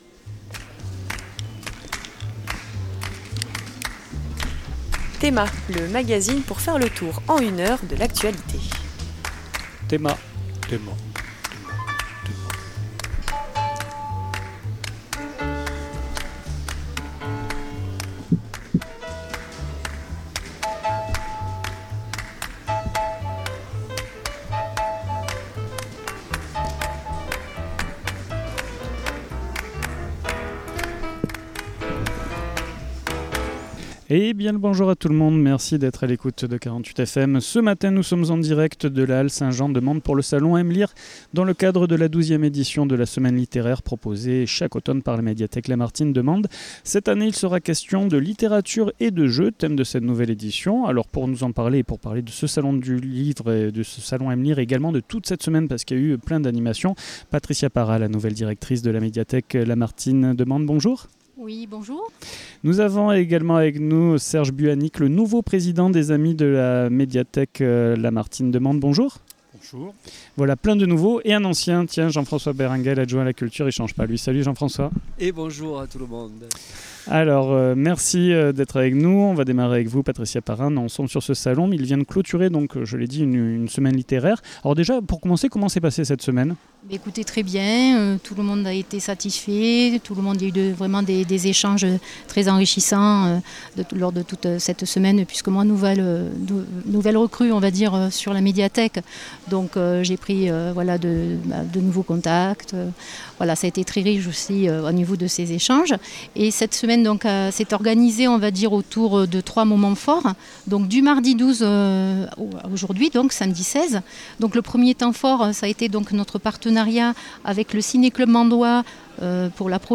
Emission en direct de la halle Saint Jean de Mende